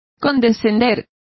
Also find out how condescended is pronounced correctly.